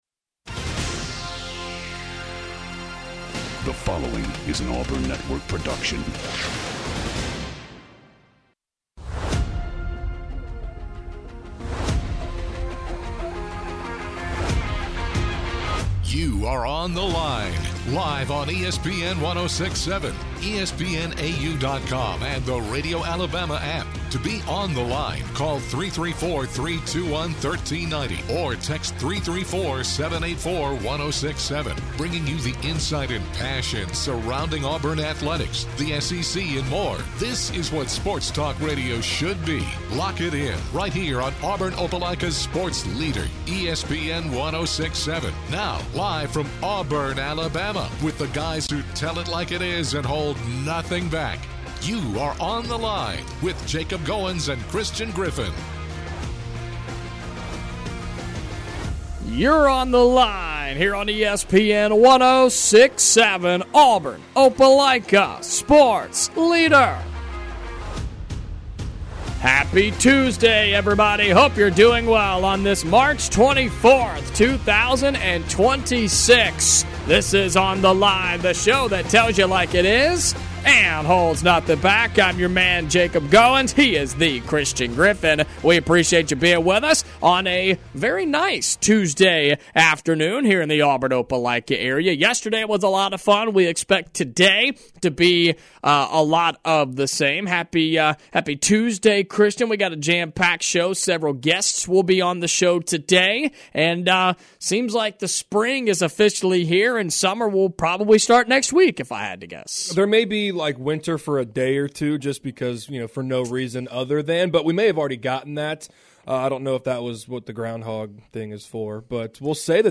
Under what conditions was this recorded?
Baseball Tuesday with a Special Guest in Studio